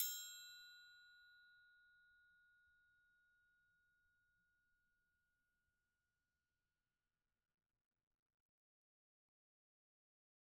Triangle3-Hit_v2_rr2_Sum.wav